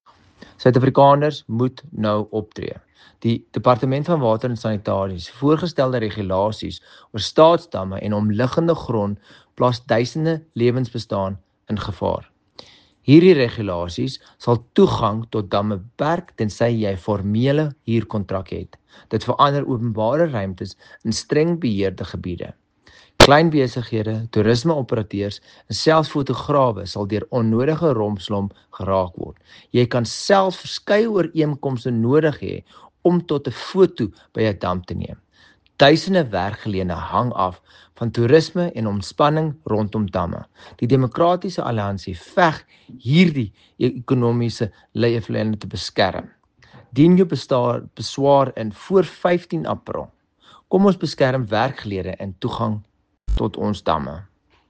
Issued by Dr Igor Scheurkogel MP – NCOP Spokesperson on Water and Sanitation
Afrikaans soundbites by Dr Igor Scheurkogel MP.